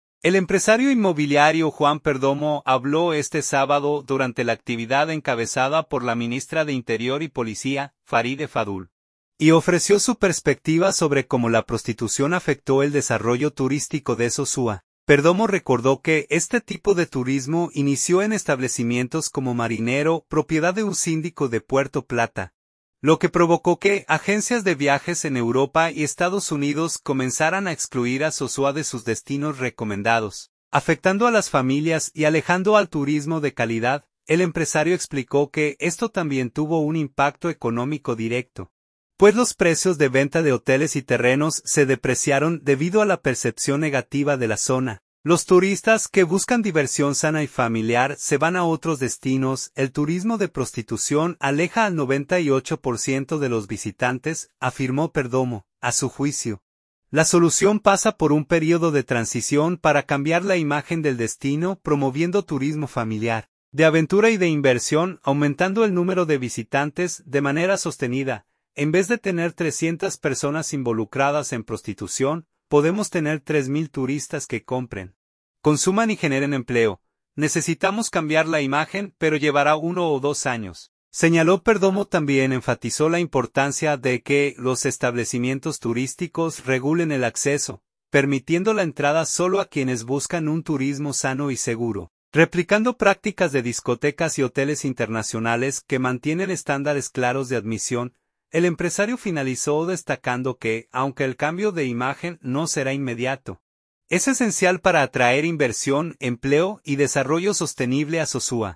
habló este sábado durante la actividad encabezada por la ministra de Interior y Policía, Faride Fadul